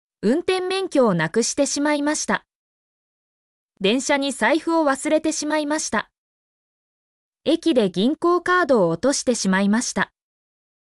mp3-output-ttsfreedotcom-58_cJVbblv2.mp3